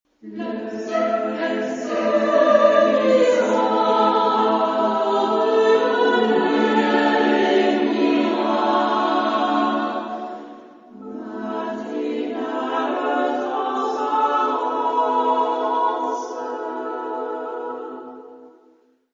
Genre-Style-Forme : Profane ; Poème ; contemporain
Type de choeur : SSAA  (4 voix égales OU égales de femmes )
Tonalité : mi mineur